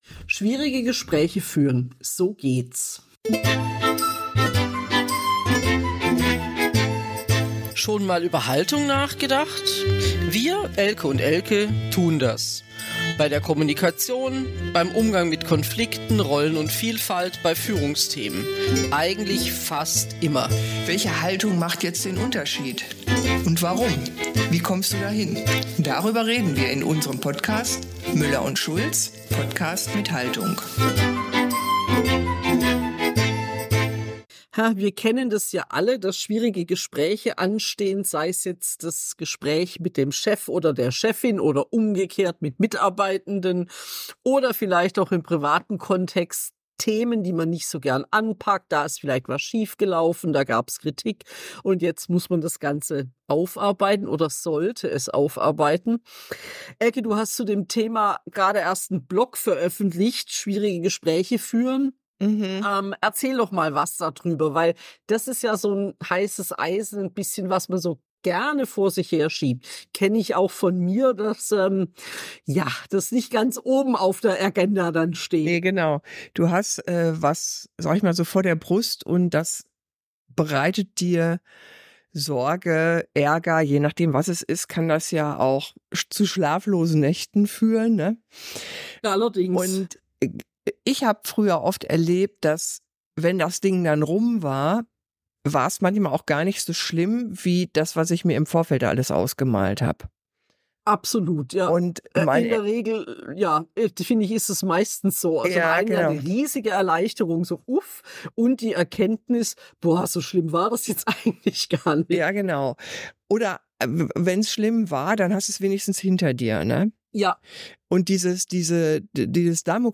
In dieser Episode diskutieren sie lebhaft über verschiedene Aspekte schwieriger Gespräche, von der richtigen Haltung bis hin zur praktischen Vorbereitung. Durch einen Mix aus persönlichen Anekdoten, praktischen Tipps und Reflexionen bieten sie dir wertvolle Einsichten und Lösungsansätze für eine effektive Gesprächsführung.